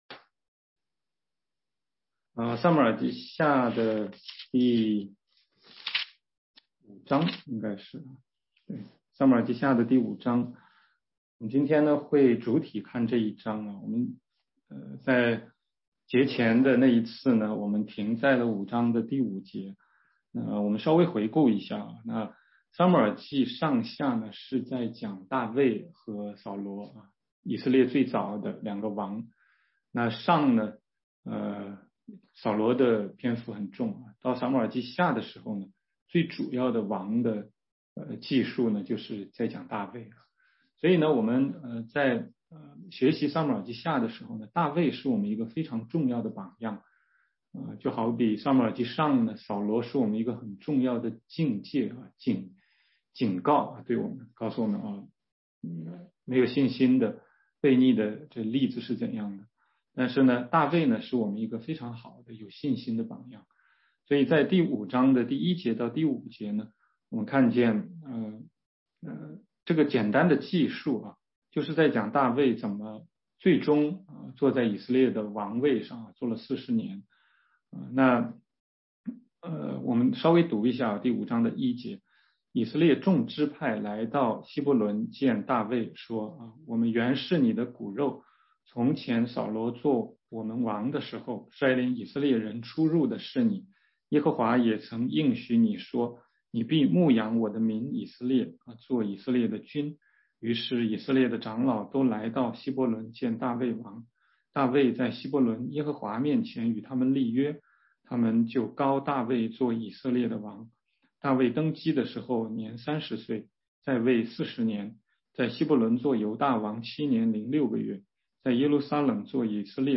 16街讲道录音 - 撒母耳记下5章6-16节：大卫攻占耶路撒冷
全中文查经